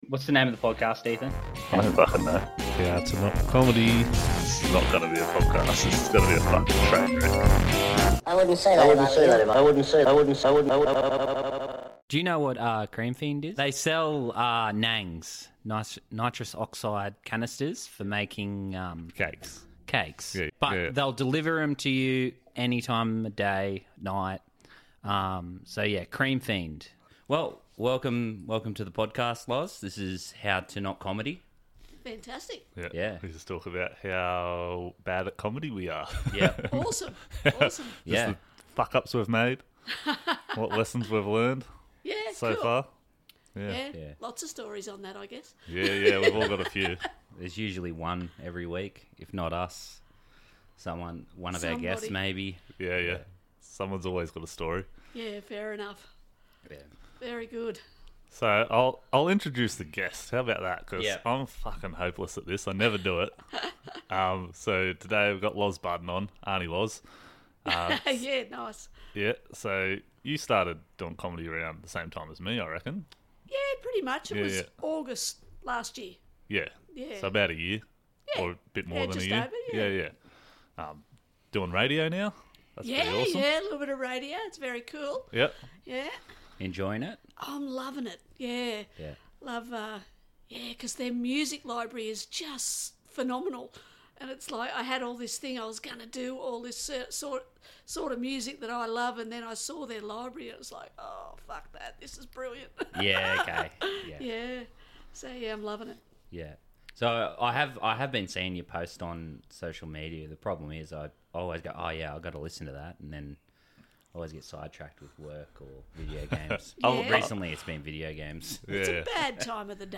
Intro Song